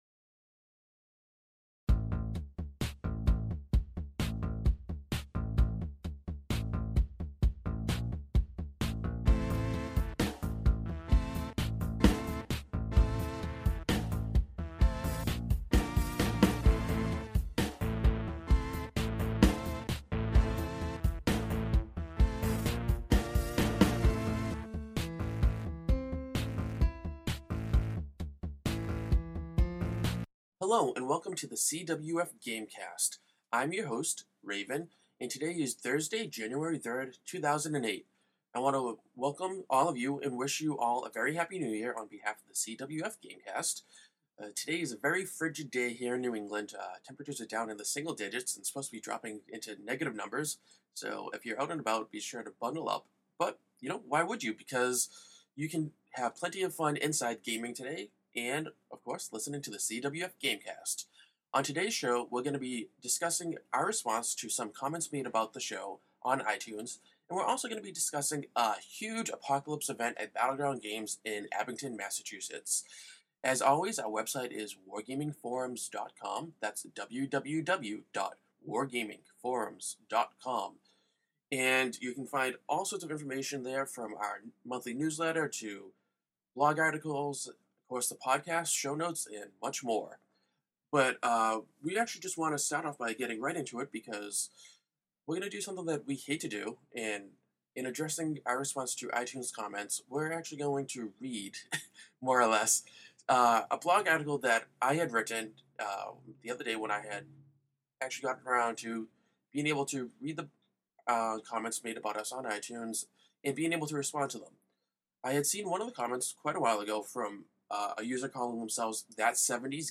Solo cast